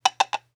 SFX_pasosCaballo2.wav